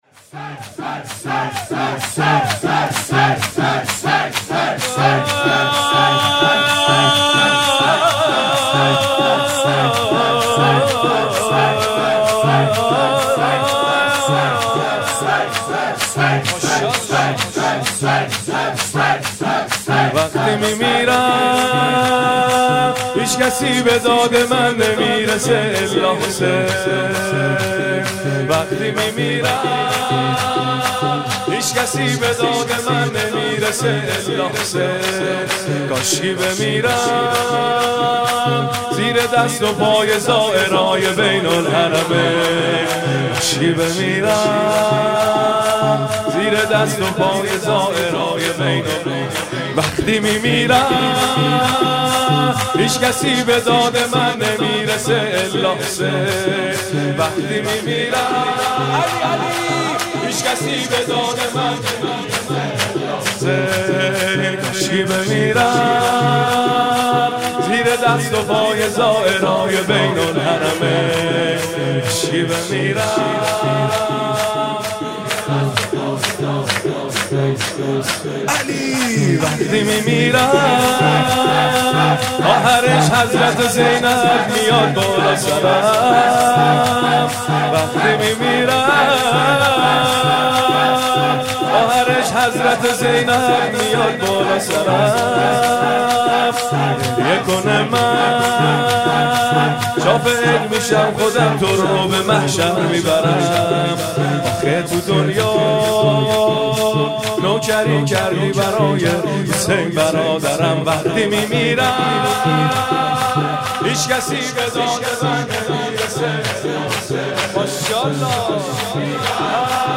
شور زیبا